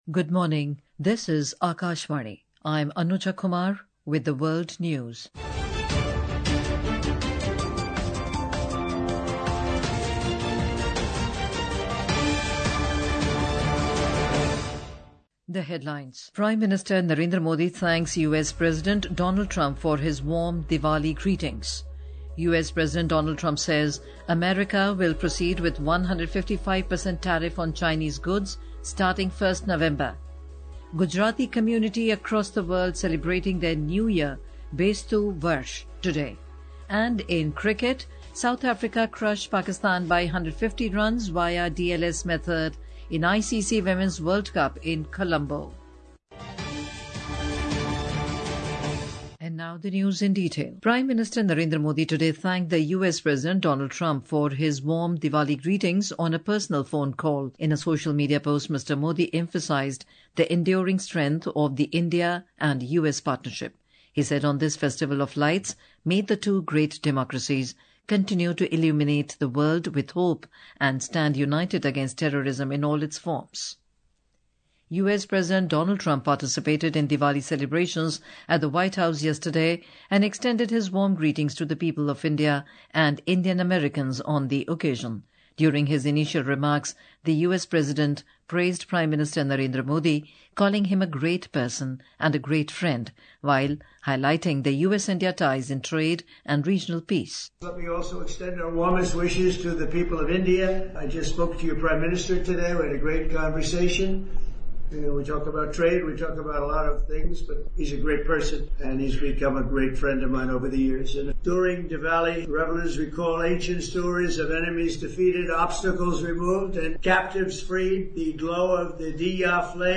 world News